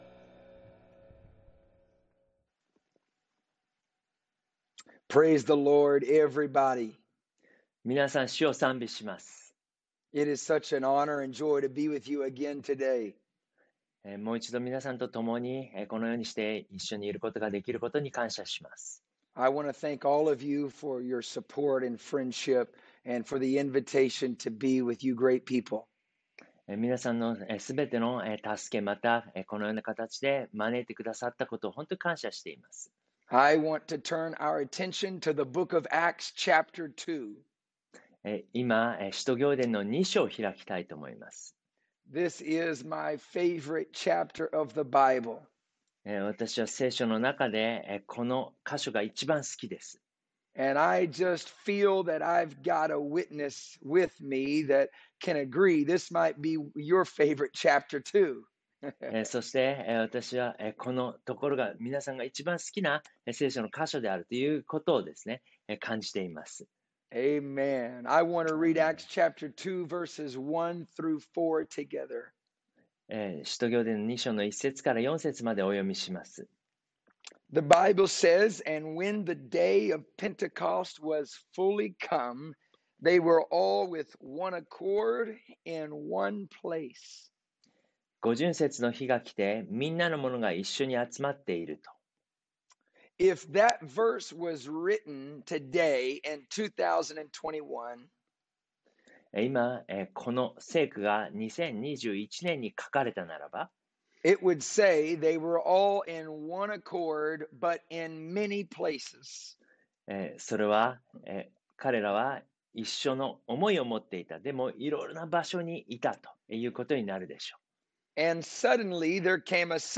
Preaching MP3, 火は落ちる / The fire will fall